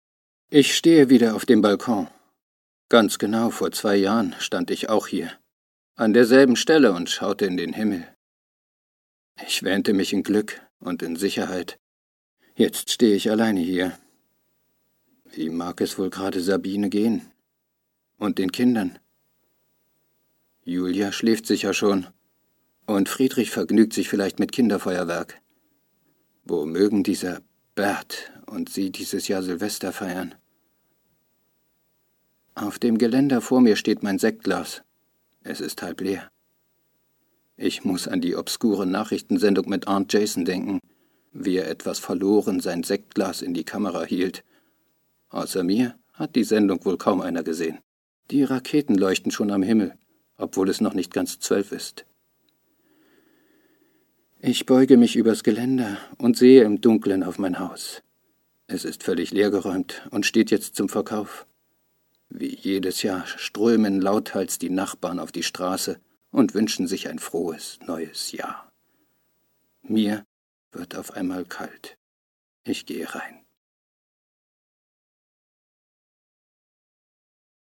Sorry, Dein Browser unterstüzt kein HTML5 Prosa Werbung / Trailer